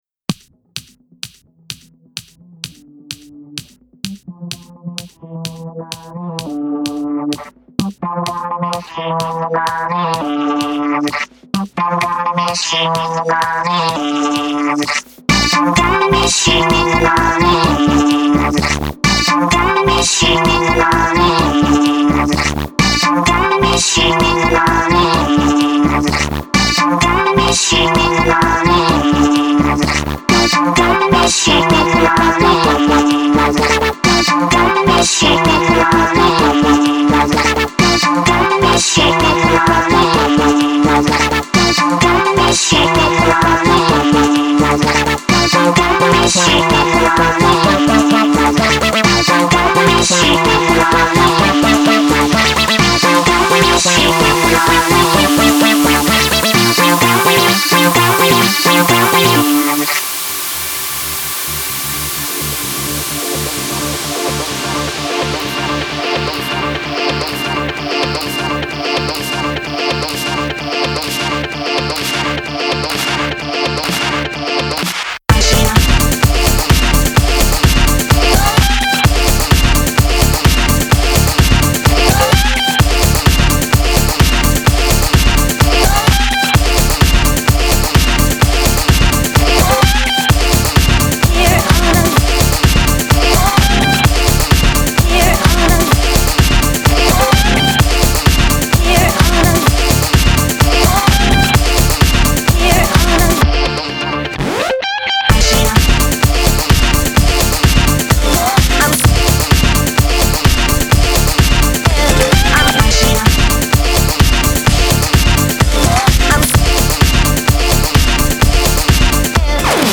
Genre: Deep House, Downtempo.